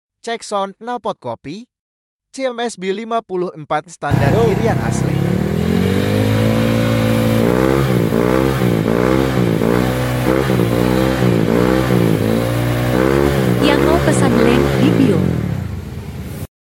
Cek sound knalpot copy cms sound effects free download
Cek sound knalpot copy cms saringan full gen 1 di vario 125 standar kirian.